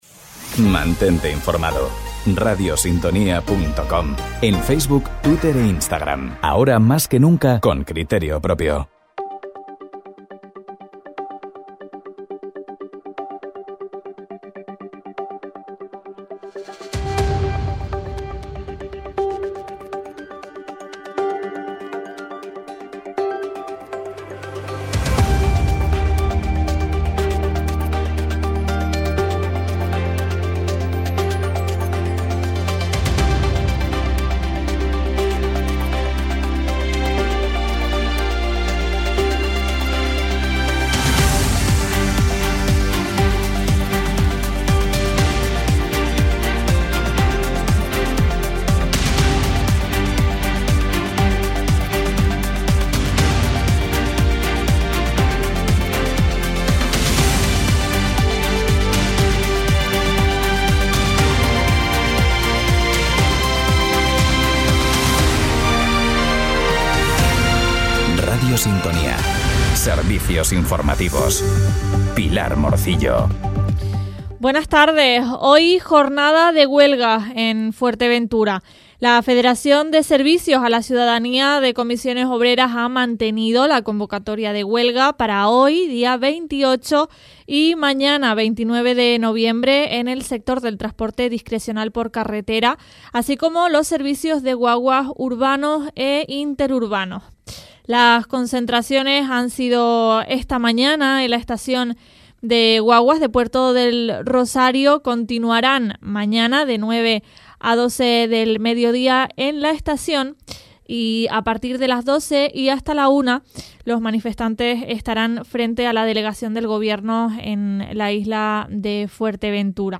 Servicios Informativos